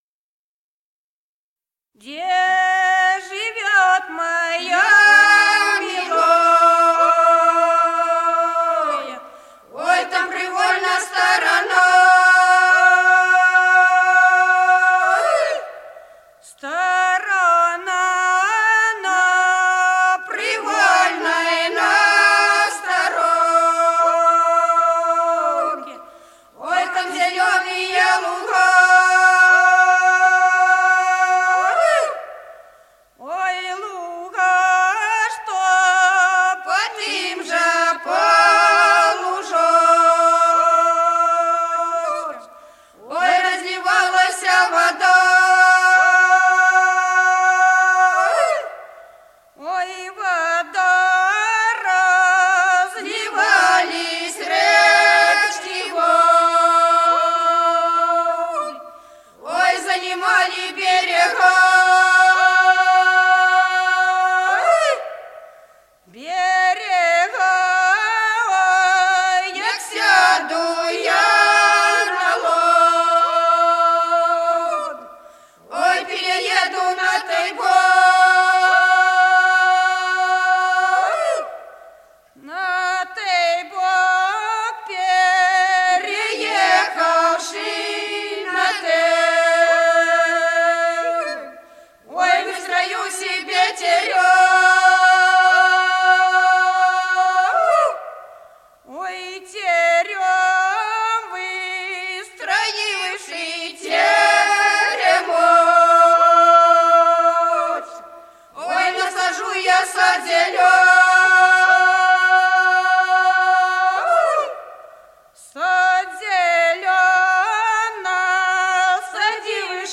Народные песни Стародубского района «Где живёть моя милая», весняная девичья.
1959 г., с. Курковичи.